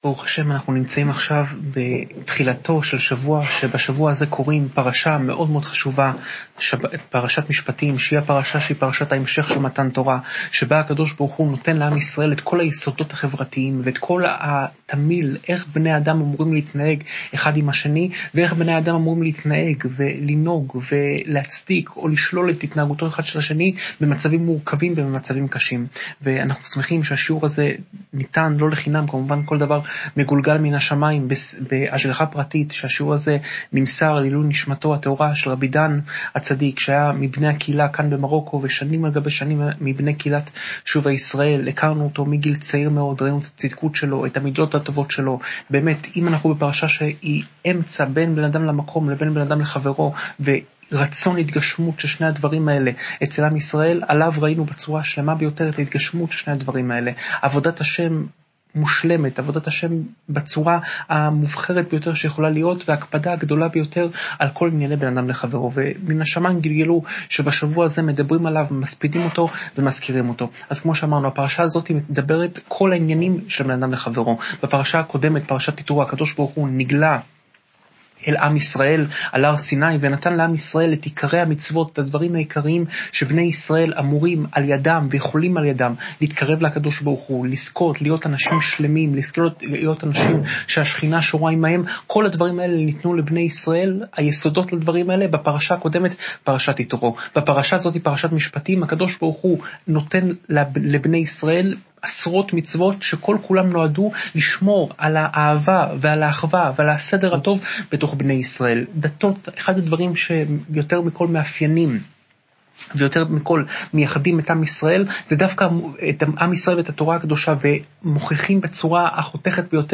שעורי תורה מפי קדשו של הרב יאשיהו יוסף פינטו
שעורי תורה מפי הרב יאשיהו יוסף פינטו